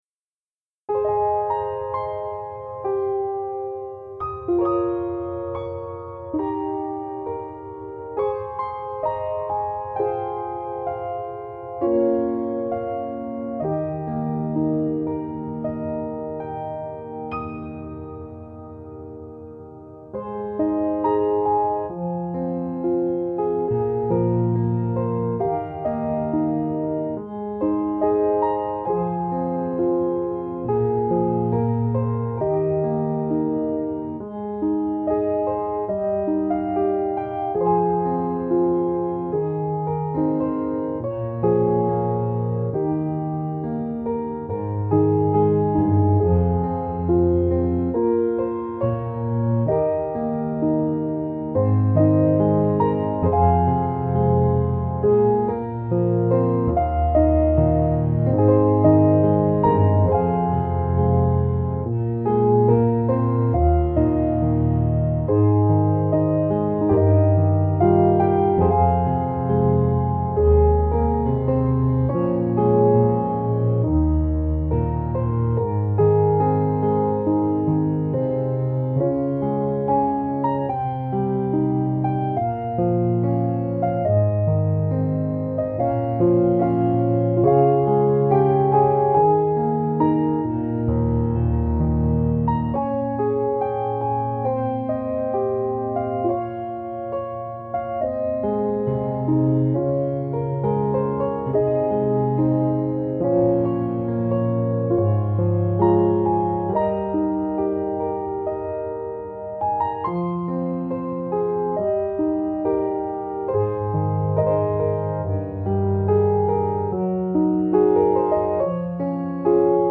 Only Tears – Solo Piano Melody
only-tears-melody.mp3